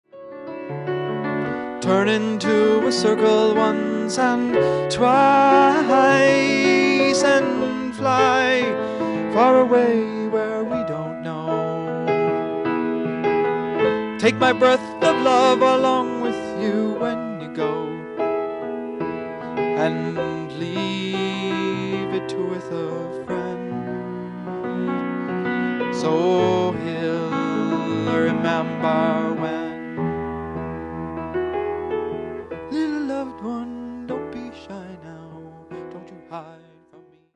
Songs on Guitar & Piano 8-12-78 (Double LP length)
2-track cassette original master